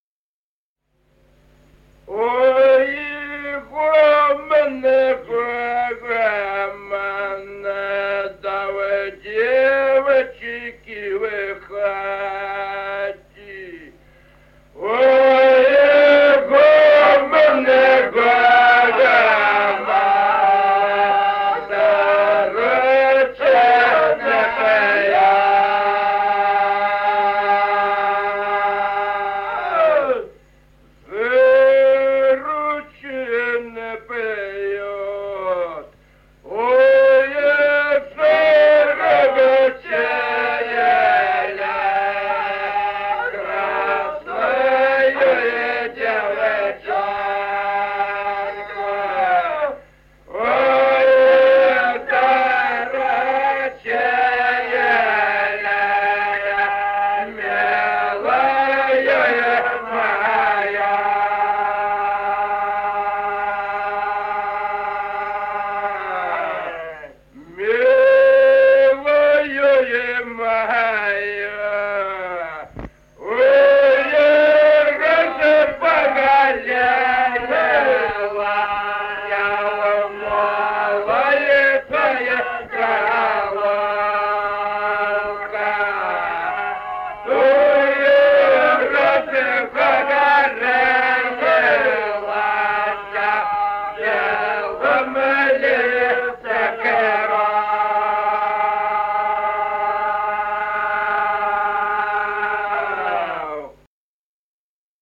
Песни села Остроглядово. Ой, гомон, гомон И 0442-06